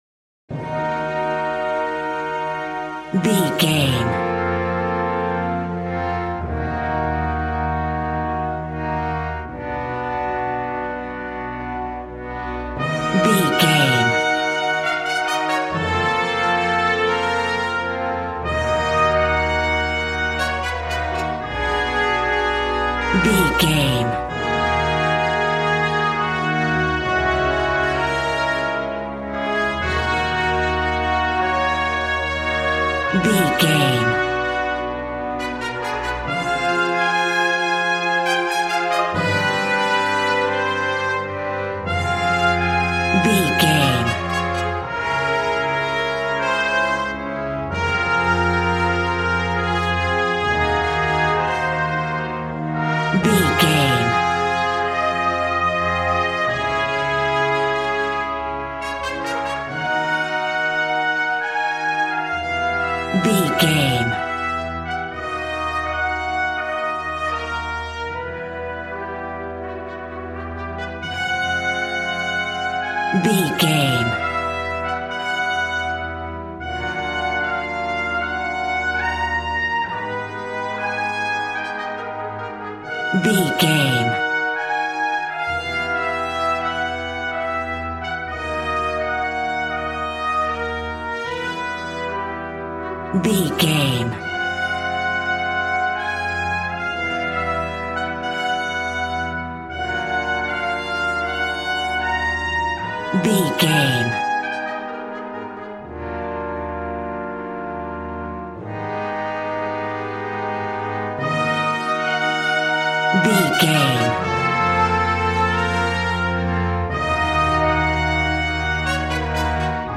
Ionian/Major
G♭
dramatic
epic
percussion
violin
cello